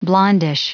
Prononciation du mot blondish en anglais (fichier audio)
Prononciation du mot : blondish